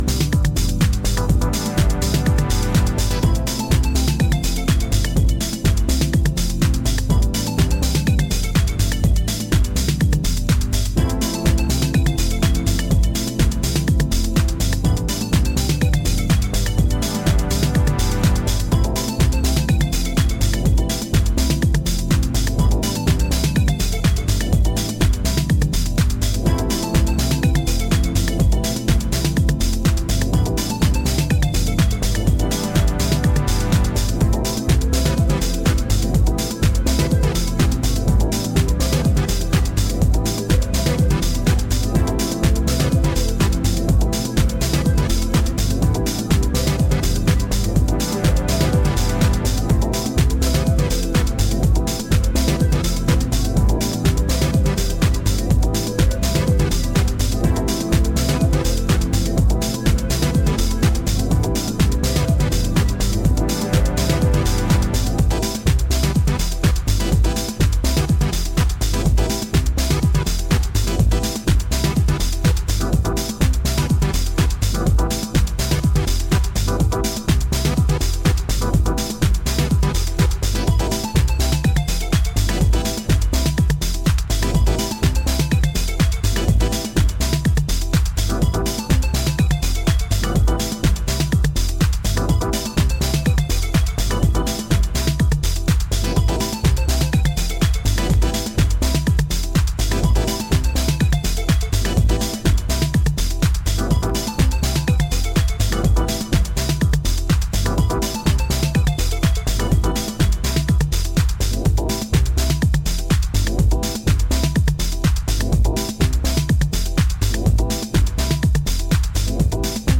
今回はダビーなフィーリングを纏いながらタイトでミニマルなディープ・ハウスを展開。